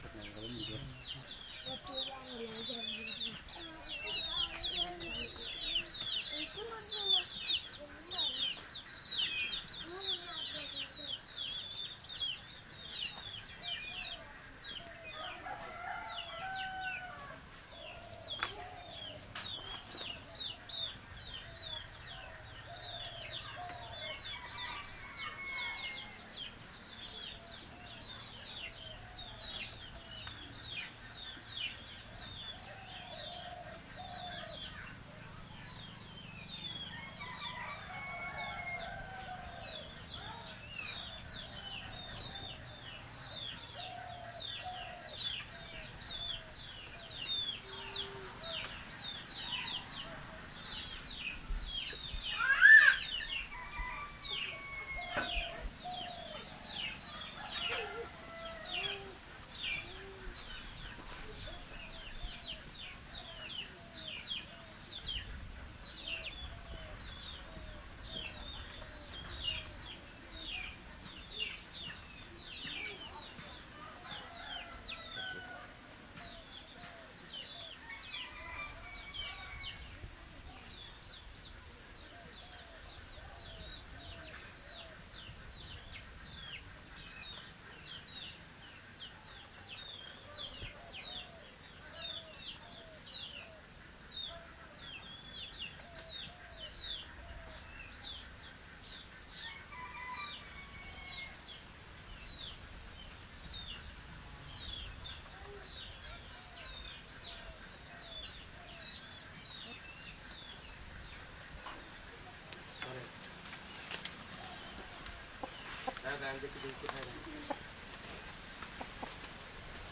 孤立的茶园的环境声音。